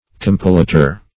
compilator - definition of compilator - synonyms, pronunciation, spelling from Free Dictionary Search Result for " compilator" : The Collaborative International Dictionary of English v.0.48: Compilator \Com"pi*la`tor\, n. [L.]